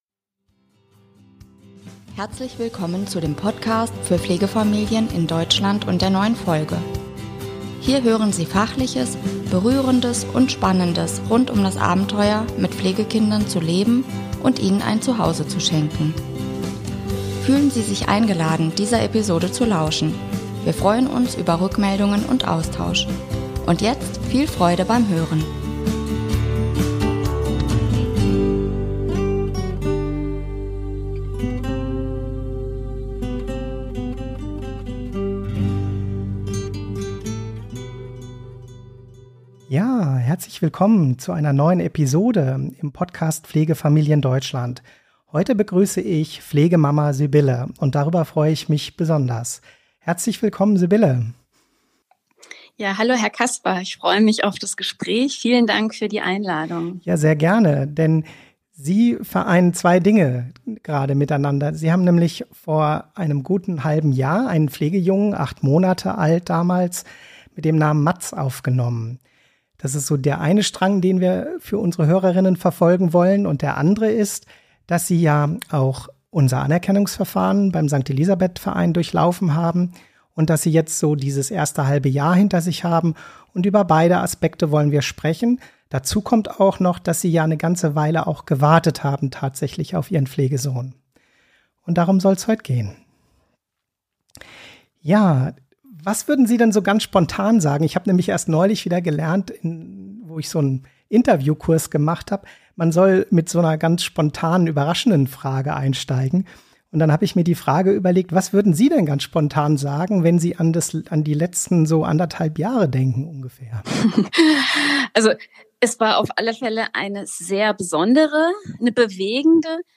Interview mit: Pflegemama